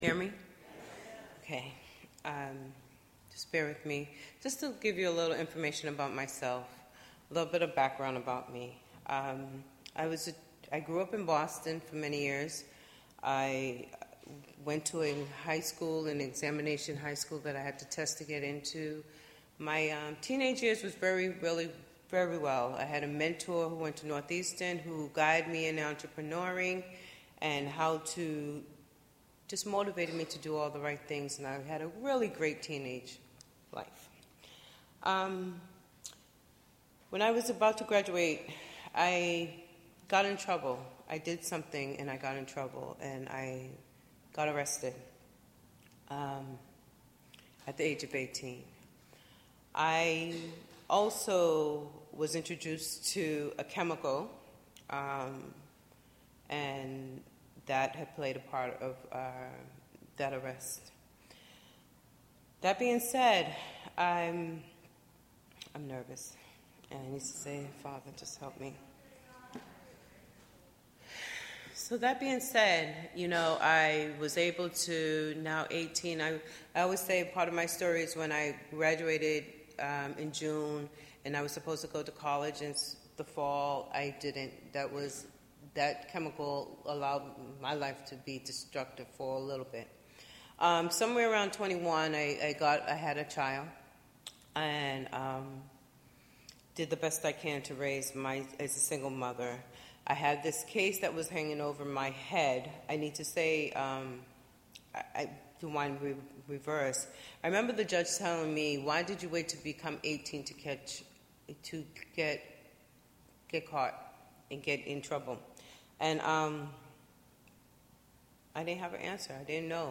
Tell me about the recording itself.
The “progressive” state of Massachusetts, which already has one of the highest incarceration rates in the country, plans to allocate $2 billion more for prisons. Our Social Justice Service explores the moral, social, and financial costs of mass incarceration.